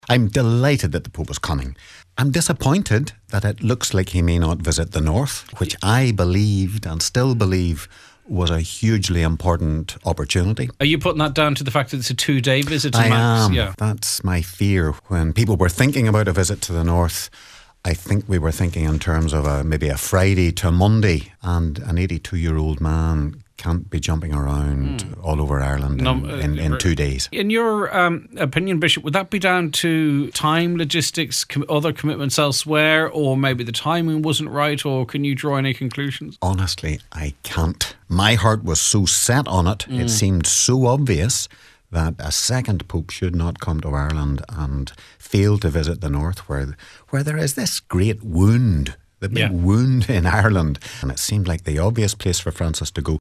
Speaking on this morning’s Nine til Noon Show, Bishop Mc Guckian suggested it’s a lost opportunity………….